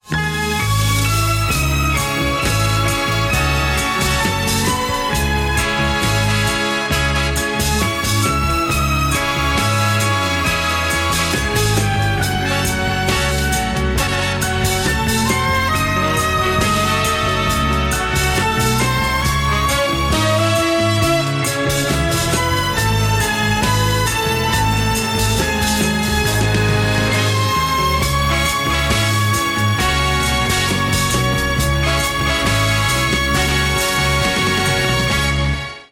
• Качество: 180, Stereo
без слов
инструментальные
романтичные
оркестр
классика